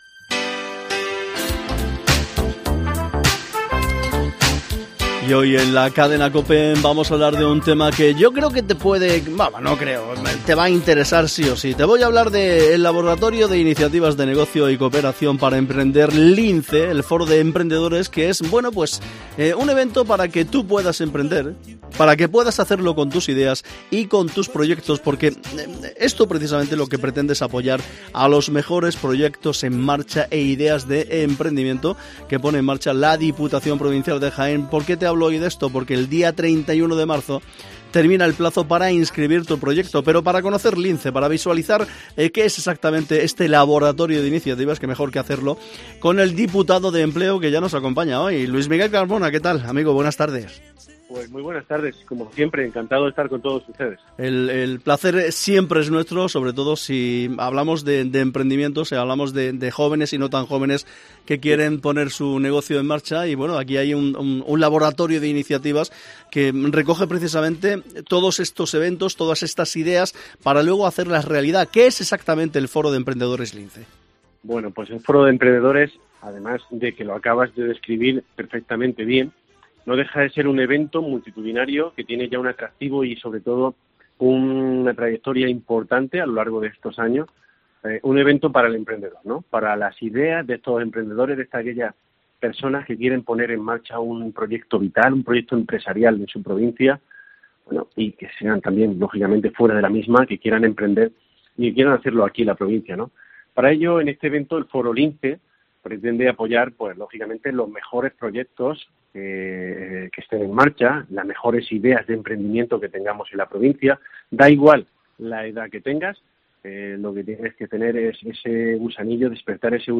Hoy en COPE hemos hablado con Luis Miguel Carmona, Diputado de Empleo, para conocer con todo lujo de detalles qué es y en qué consiste Foro LINCE.